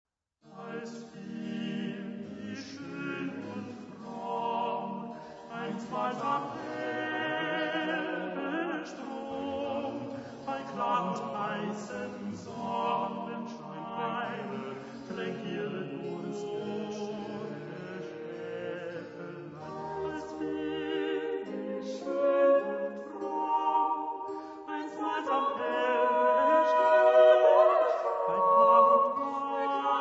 Personaggi e interpreti: liuto ; Junghanel, Konrad